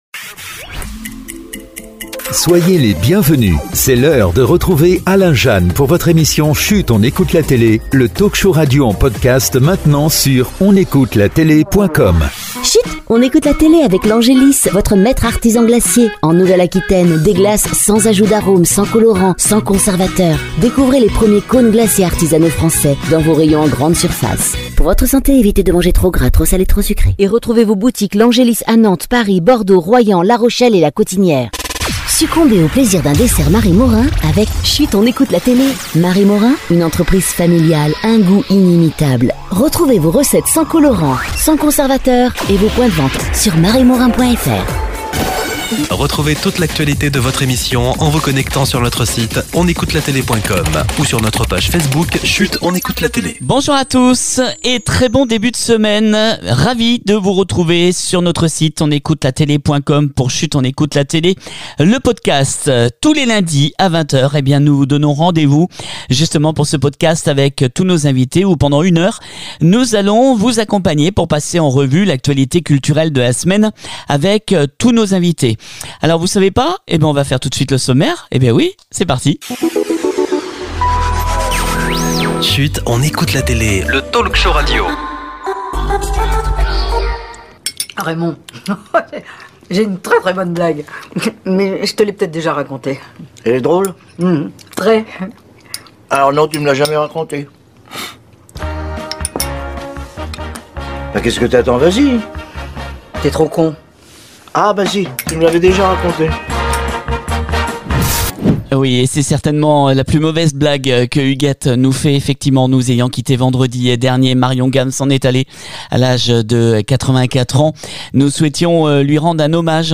enfin en exclusivité un entretien exceptionnel enregistré au Festival de Luchon de Jean Jacques Annaud